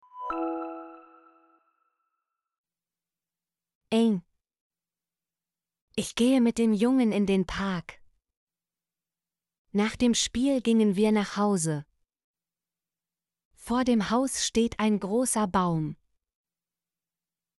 em - Example Sentences & Pronunciation, German Frequency List